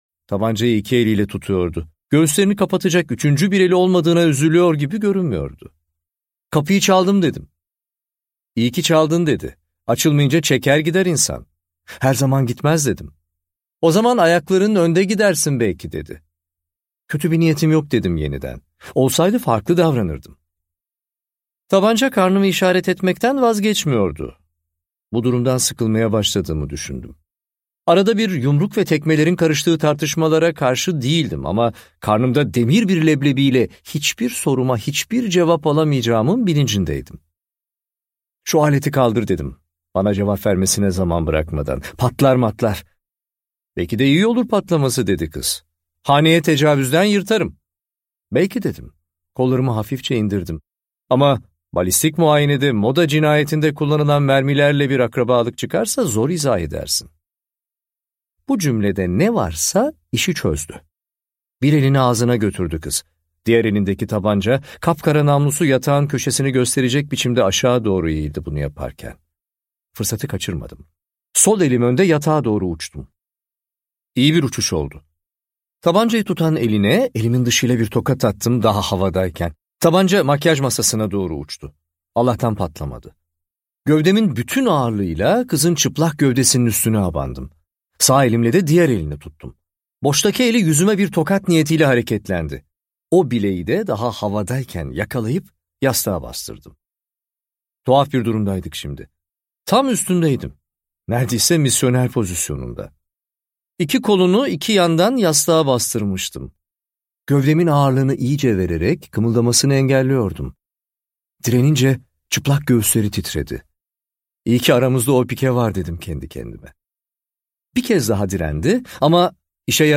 Son Ceset - Seslenen Kitap